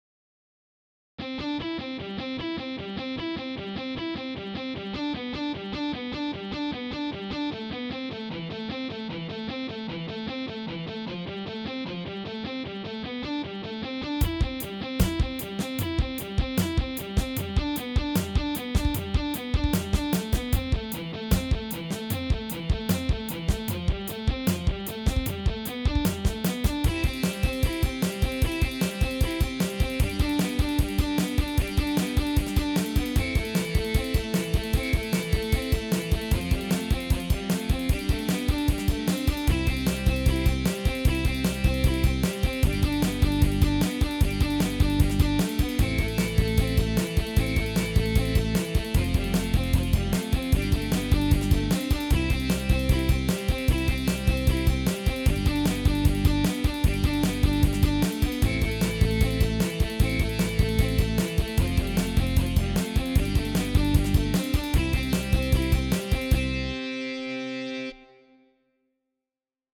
Bakgrund-4_Elgitarr-Elbas-Tr-.mp3